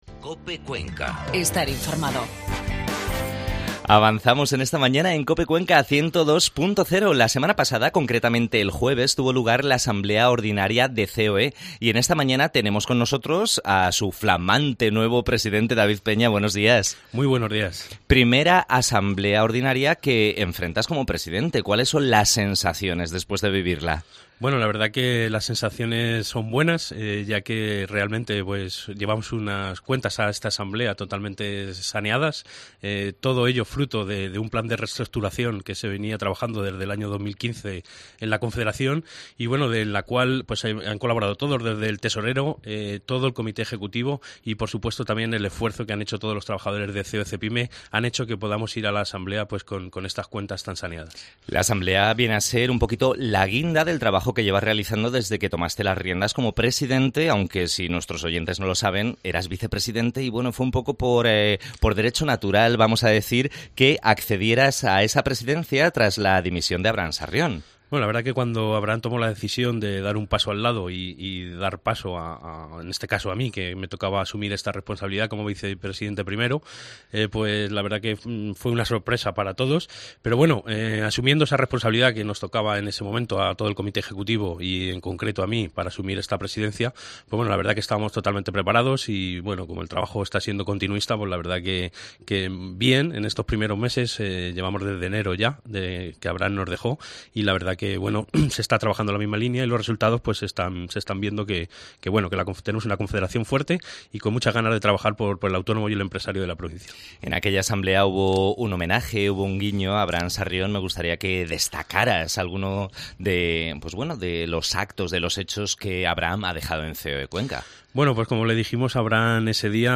Entervista